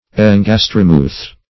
Search Result for " engastrimuth" : The Collaborative International Dictionary of English v.0.48: Engastrimuth \En*gas"tri*muth\, n. [Gr.